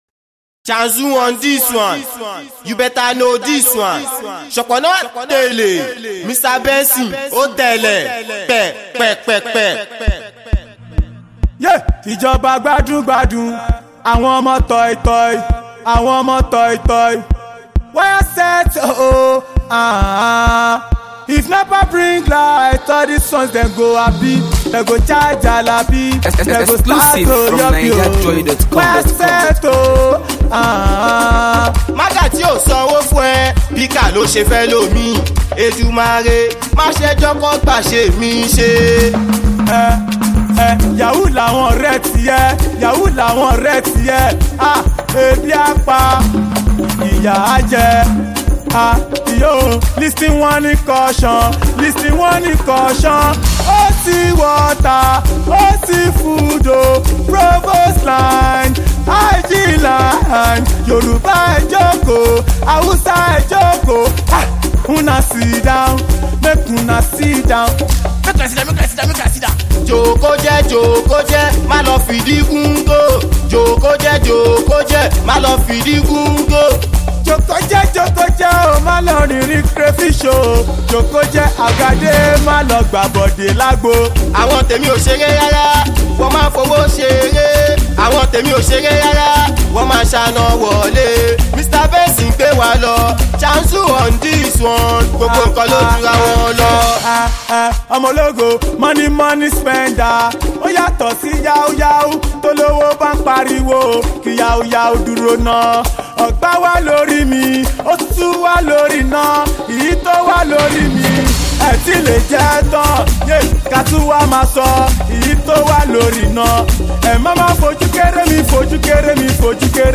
Nigerian Afrobeats